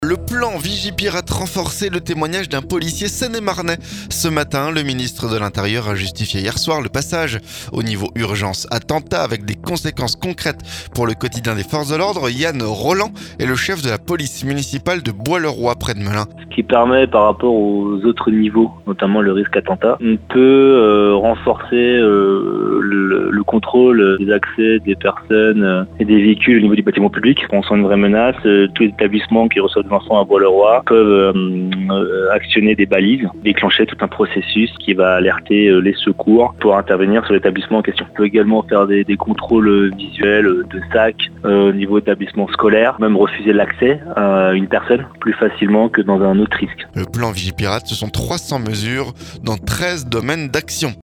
VIGIPIRATE - Ce policier seine-et-marnais témoigne